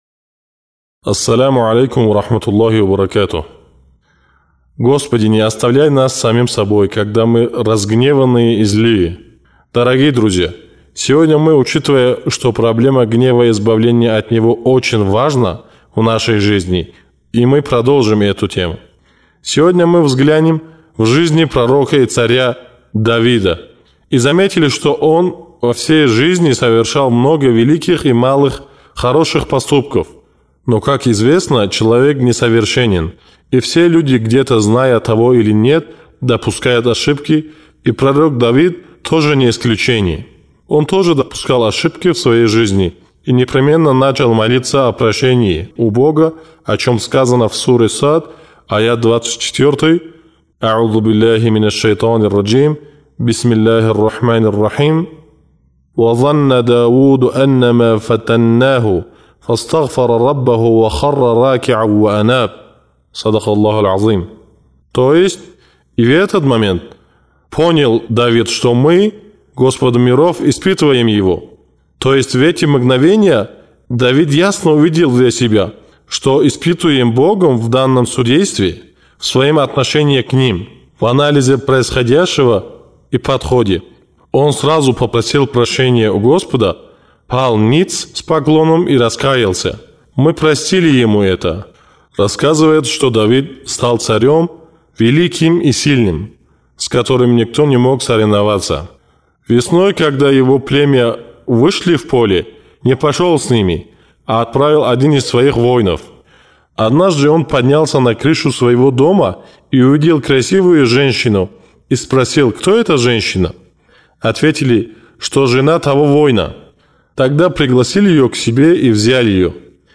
Чтобы найти ответы на все эти вопросы, вы можете прослушать шестую речь – «Как избавиться от гнева».